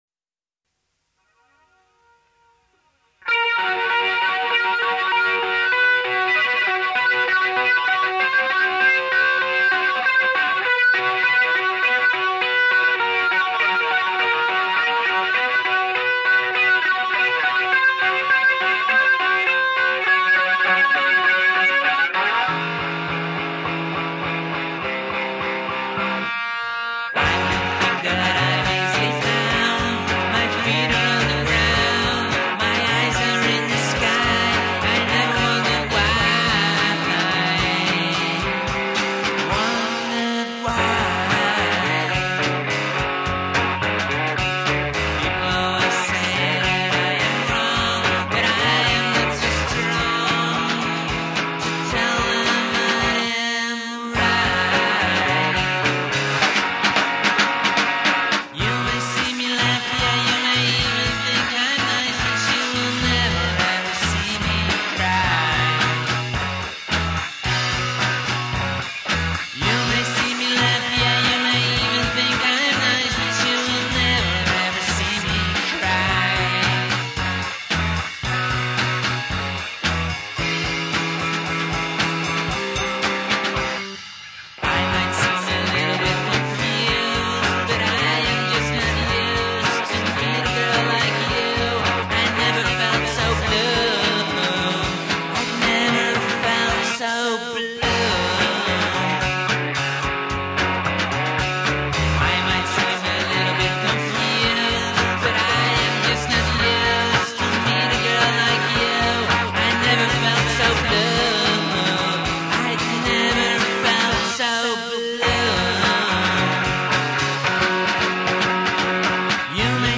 where: recorded at AMP (Amsterdam)
trivia: pretentious opening, fun solo